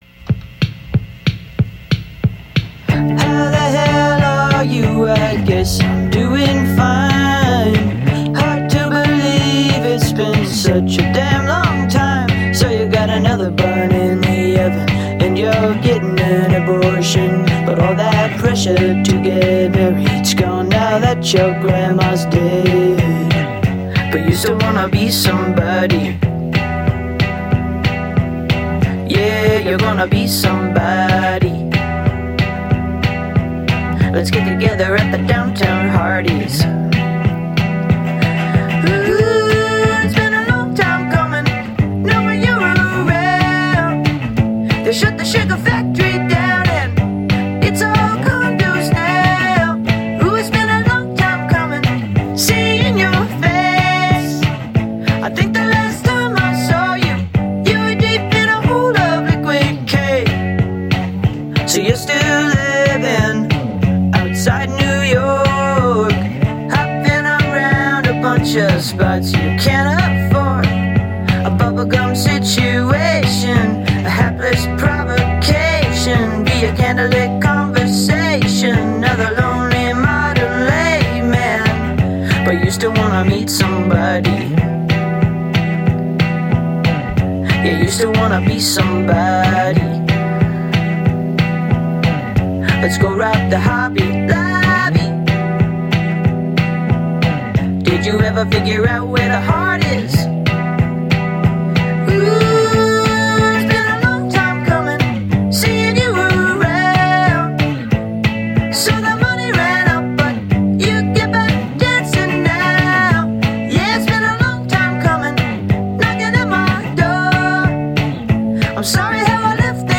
инди вариант